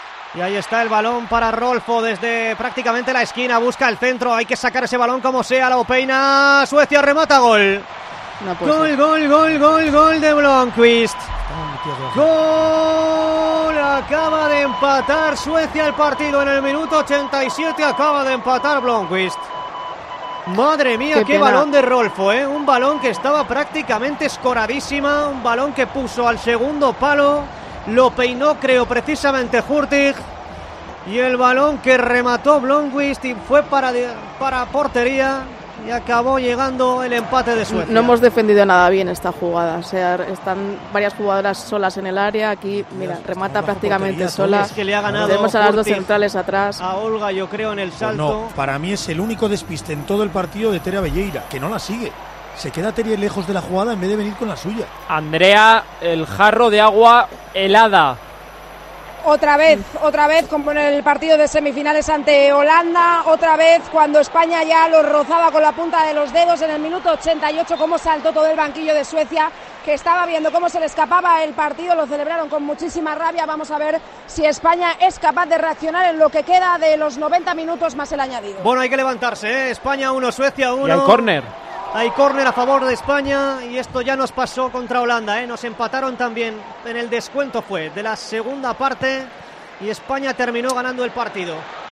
Revive la retransmisión del España-Suecia en Tiempo de Juego